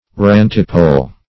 Rantipole \Rant"i*pole\ (r[a^]nt"[i^]*p[=o]l), n. [Ranty + pole,